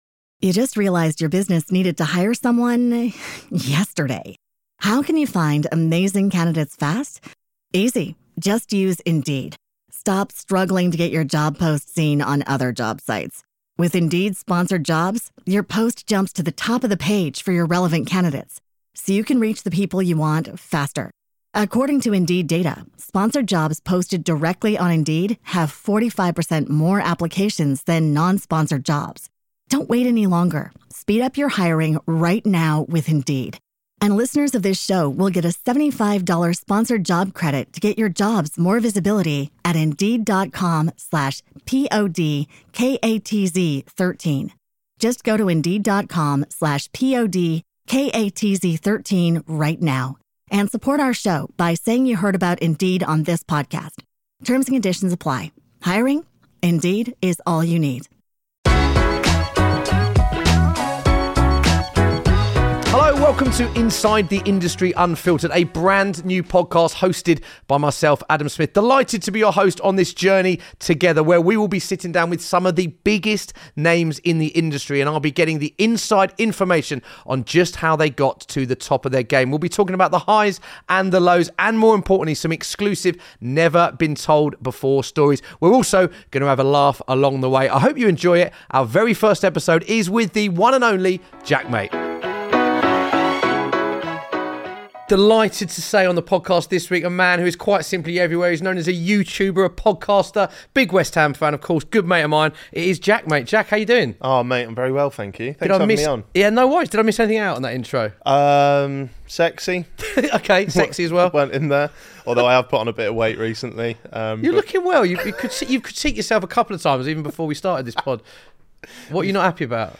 Of course, we will be having a laugh along the way as well. Guests will be giving you their secrets to success and as well as advice and tips on how to make it to the top.